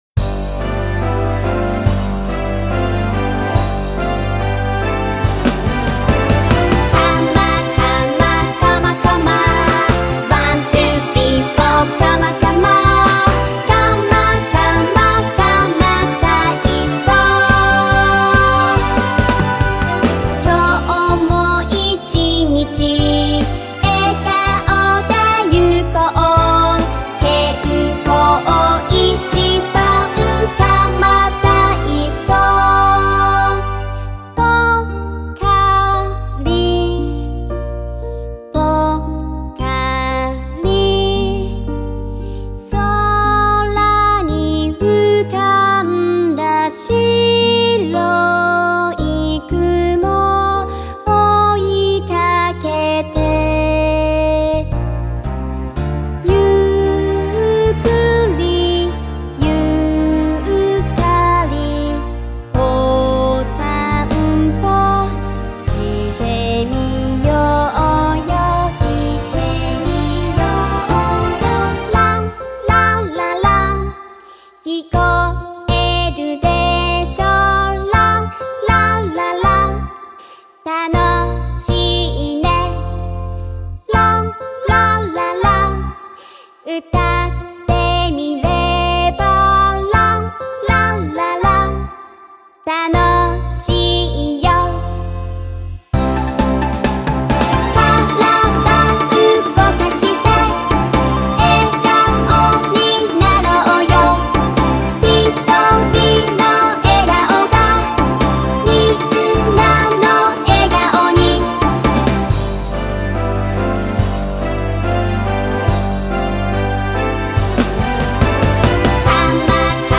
音楽